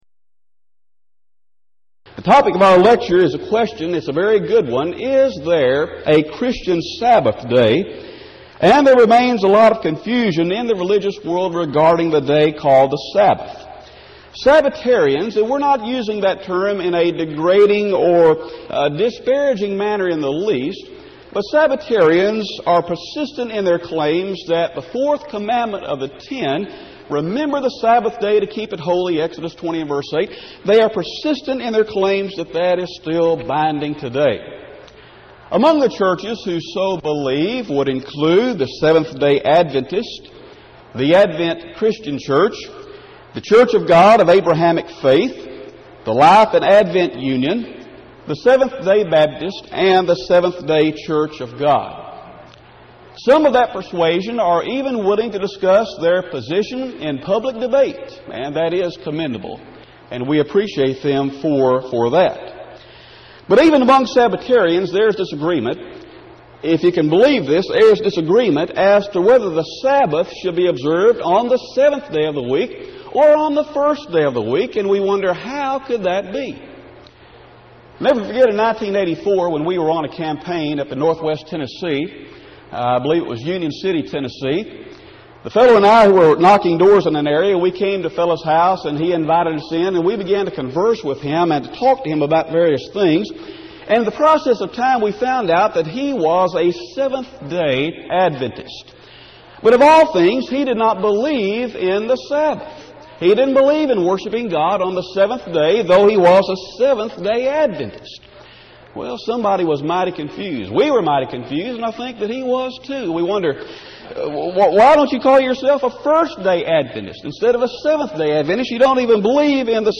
Event: 1996 Power Lectures
lecture